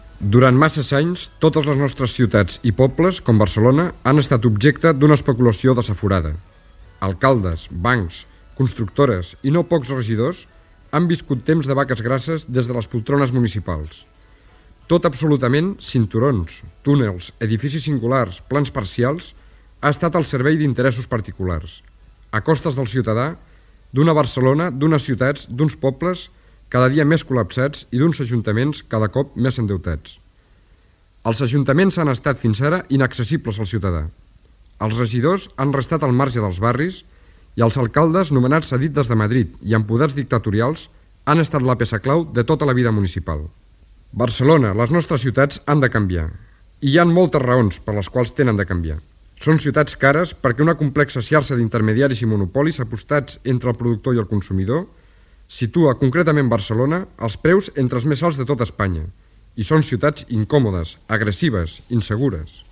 Espais gratuïts de propaganda electoral
Missatge de Josep Miquel Abad candidat del Partit Socialista Unificat de Catalunya (PSUC) a l'Ajuntament de Barcelona